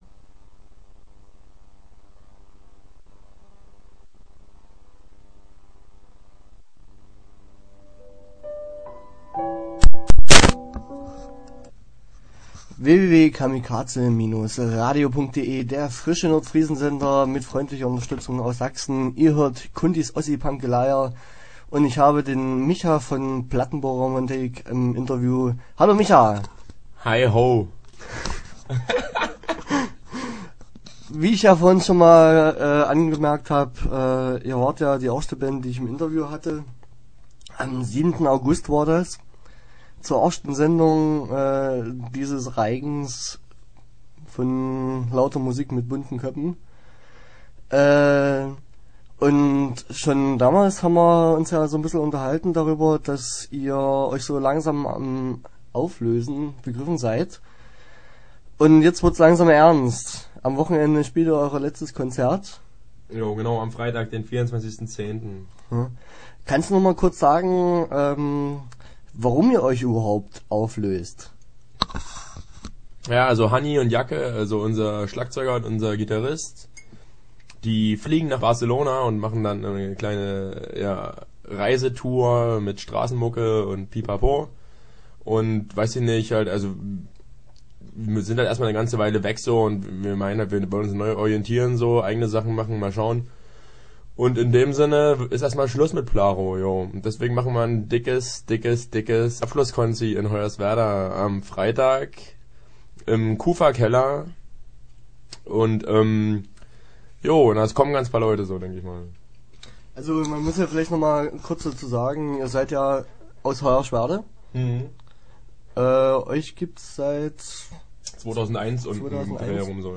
Interview Teil 1 (10:15)